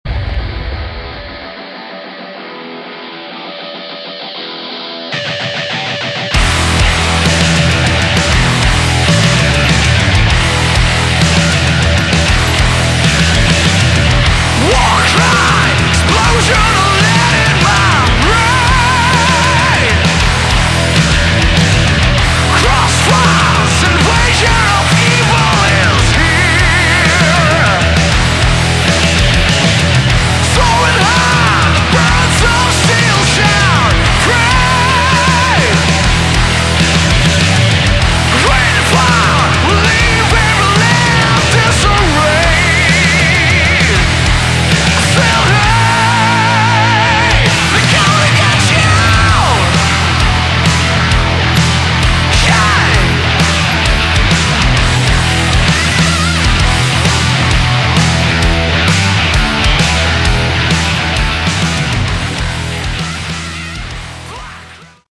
Category: Hard Rock
Vocals, Guitars
Lead Guitars